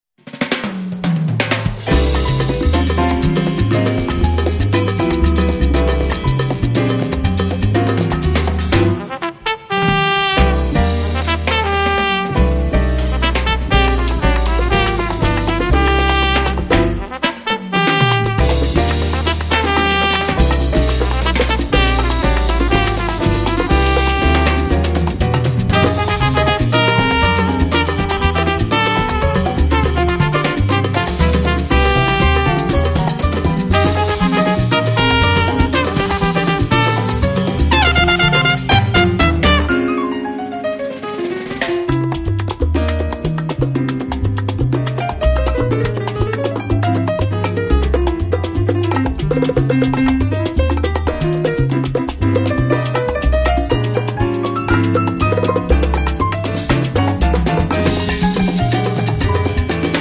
drums, timbales, congas, bongo, guiro, bata & backing vocals
bass & baby bass
percussion
trumpet & flugelhorn, piano, keyboards
trombone
tenor sax
piano
vocals & backing vocals